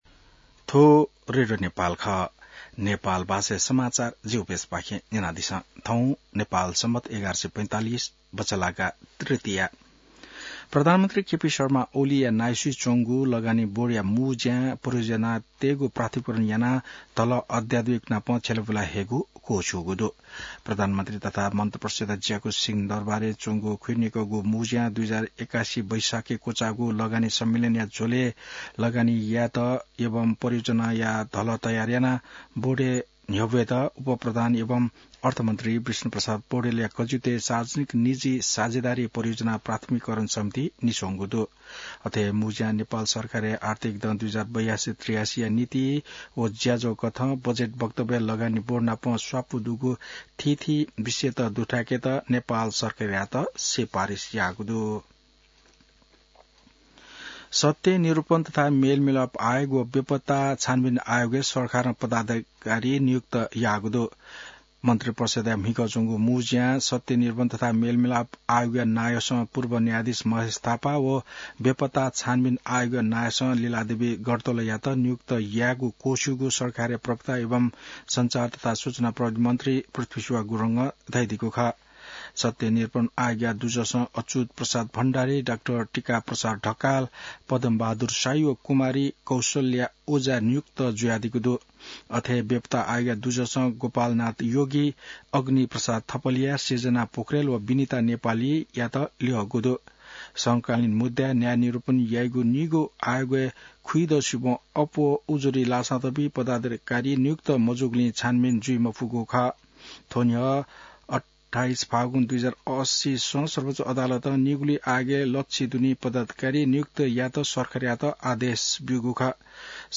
नेपाल भाषामा समाचार : १ जेठ , २०८२